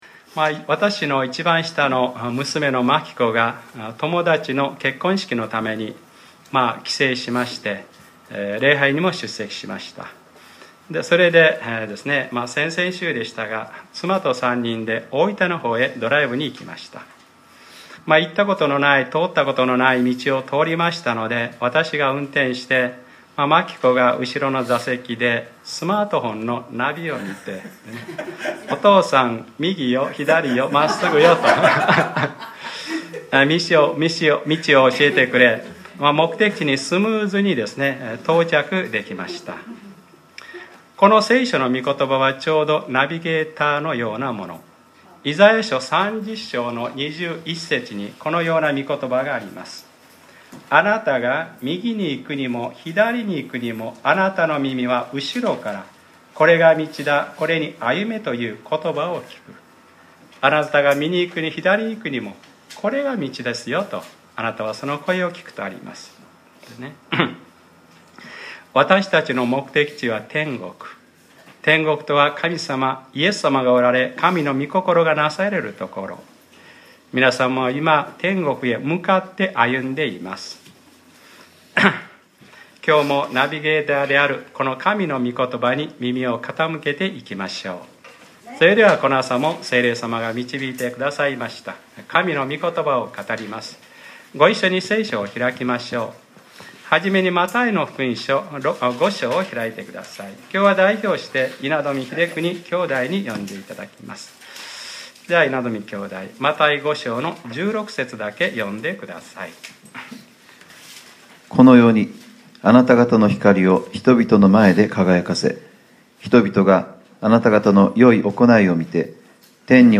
2015年09月20日（日）礼拝説教 『聖徒たちを整えて奉仕の働きをさせ』 | クライストチャーチ久留米教会